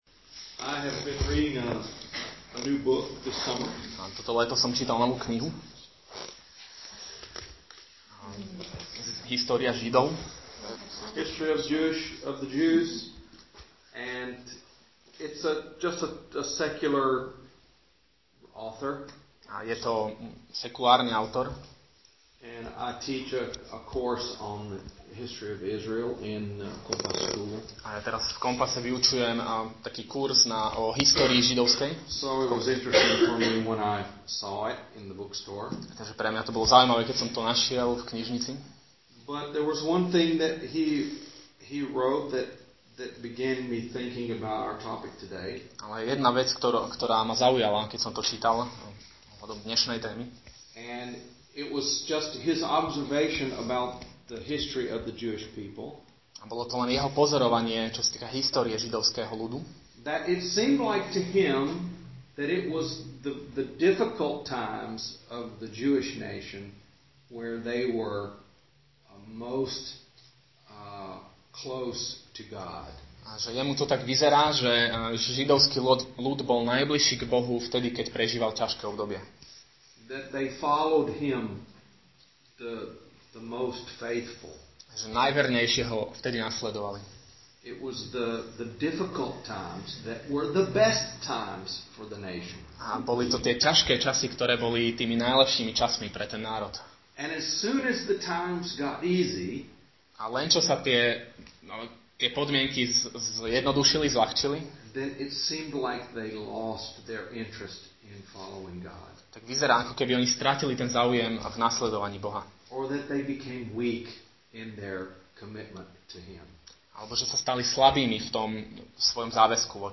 Nahrávka kázne Kresťanského centra Nový začiatok z 7. augusta 2016